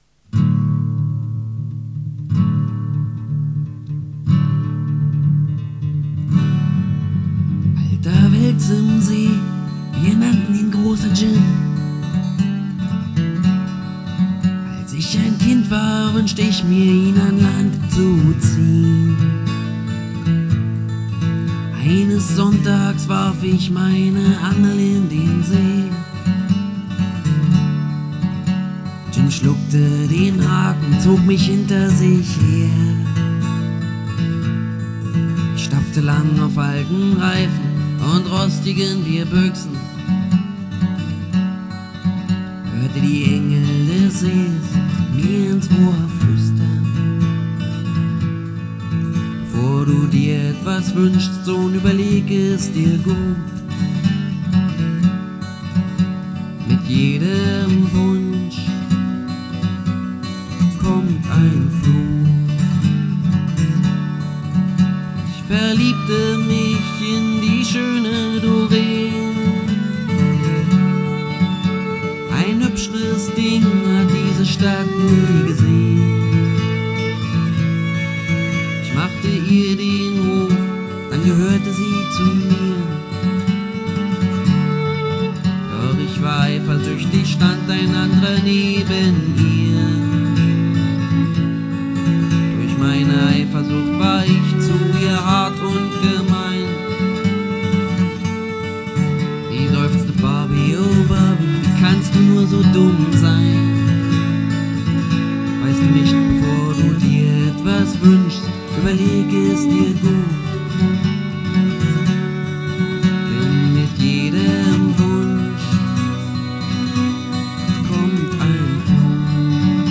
Gesang, Gitarre
Querflöte